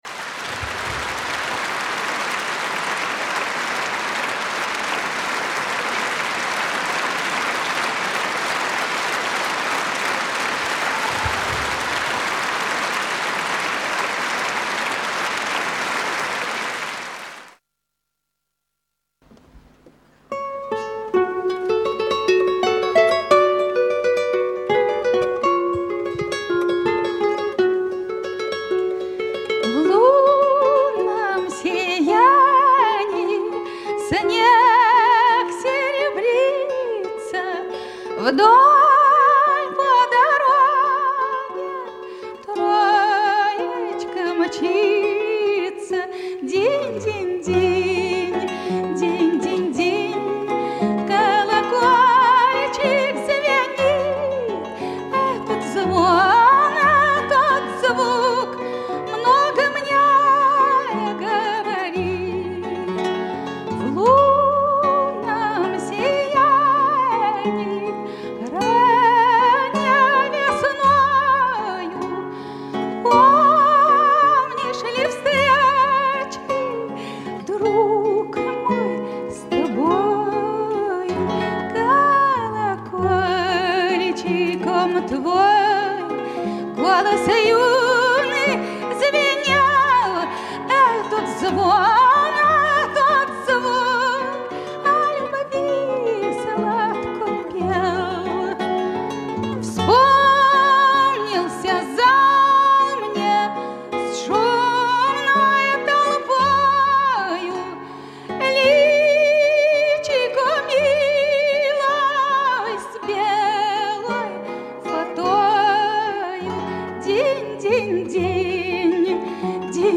Старинный русский романс